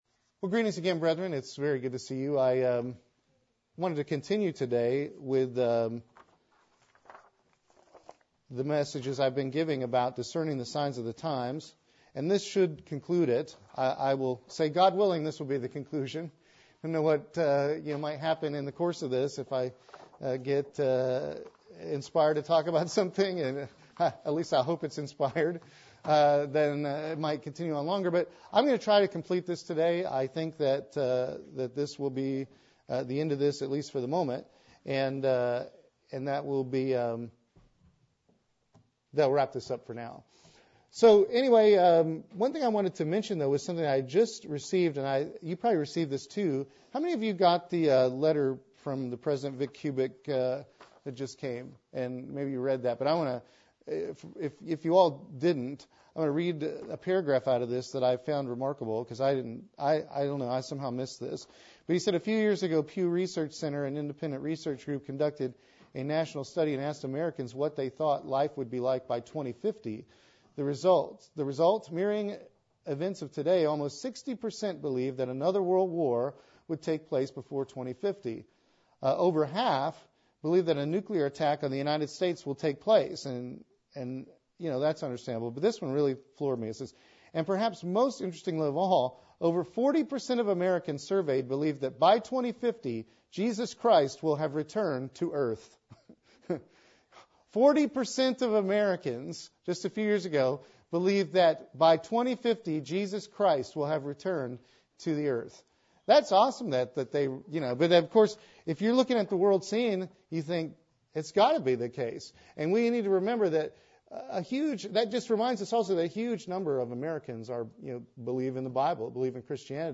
Given in Columbia - Fulton, MO
UCG Sermon Studying the bible?